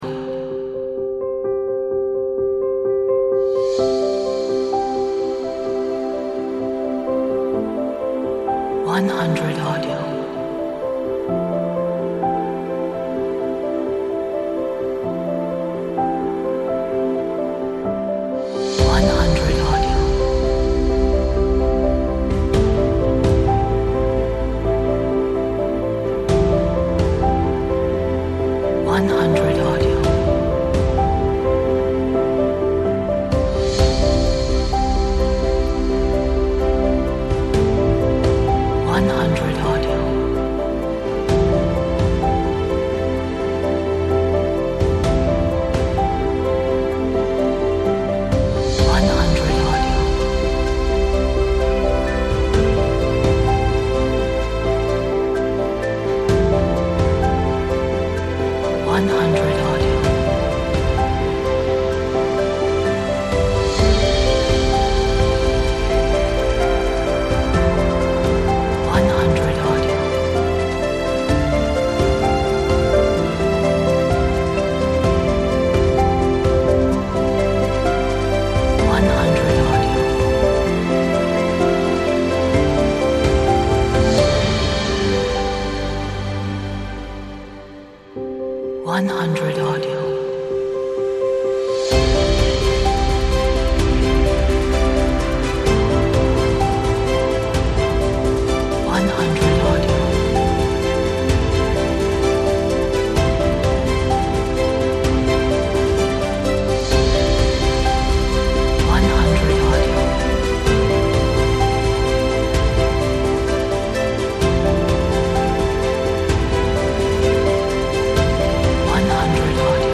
Romantic Epic Piano